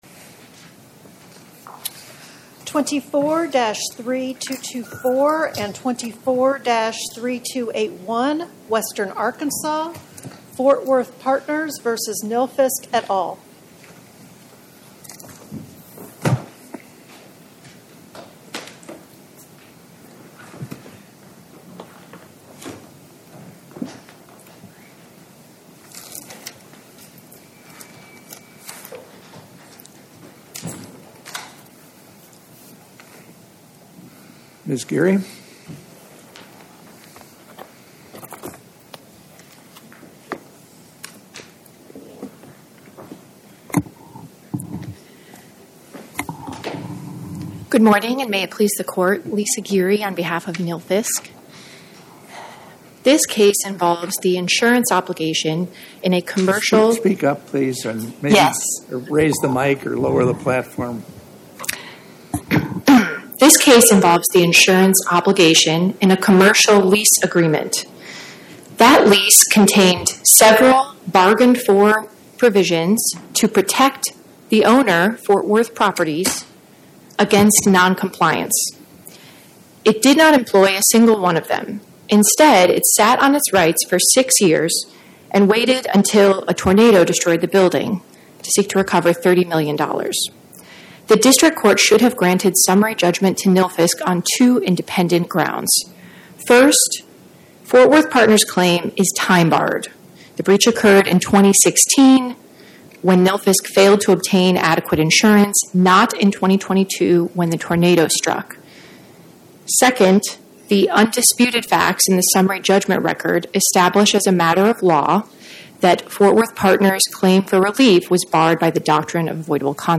My Sentiment & Notes 24-3224: Fort Worth Partners, LLC vs Nilfisk, Inc. Podcast: Oral Arguments from the Eighth Circuit U.S. Court of Appeals Published On: Wed Sep 17 2025 Description: Oral argument argued before the Eighth Circuit U.S. Court of Appeals on or about 09/17/2025